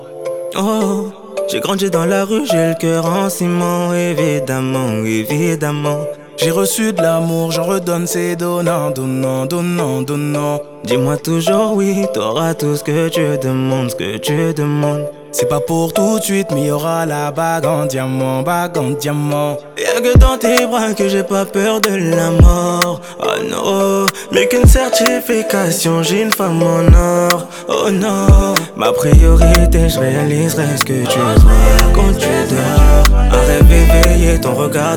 Жанр: Поп / Африканская музыка